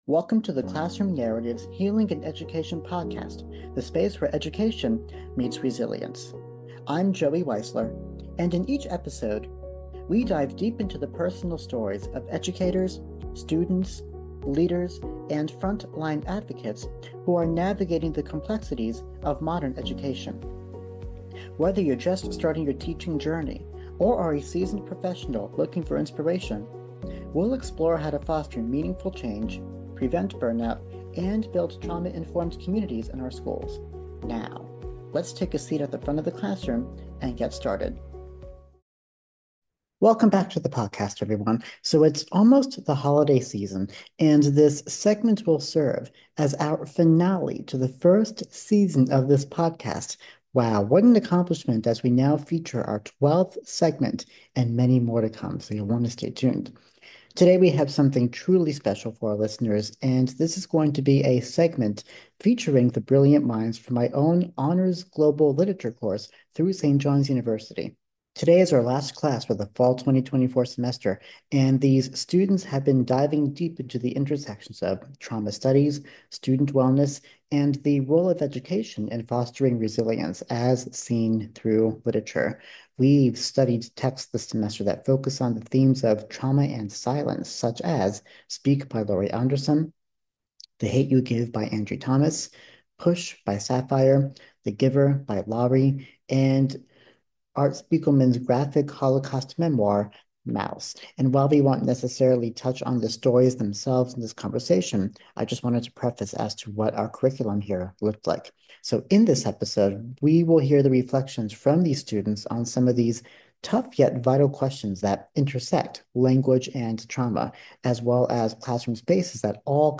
In this season finale of The Classroom Narratives Podcast, we’re joined by students from St. John’s University’s Honors Global Literature class for a profound discussion on the intersection of trauma, storytelling, and resilience.